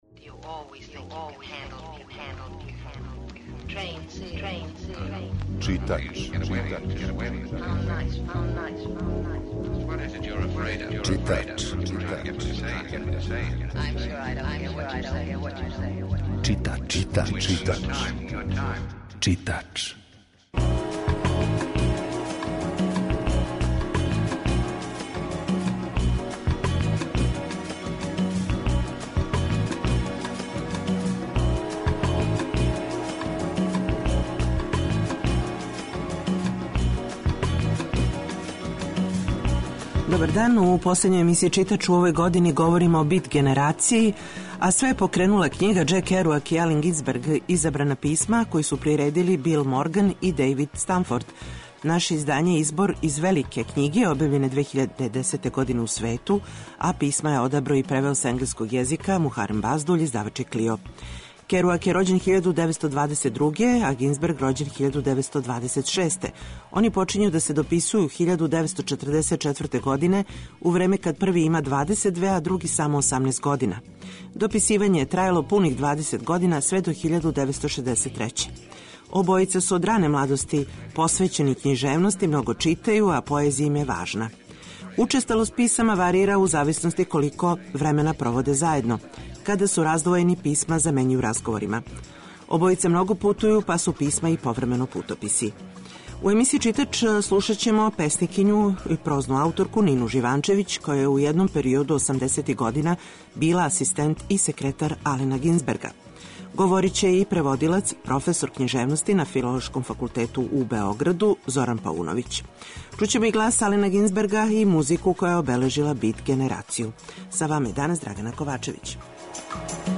Ово је био повод да емисију, уз доста музике, посветимо бит генерацији којој су припадали Ален Гинсберг и Џек Керуак, али и други писци.